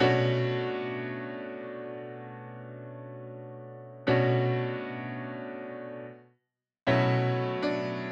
12 Piano PT3.wav